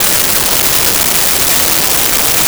Shopping Mall
Shopping Mall.wav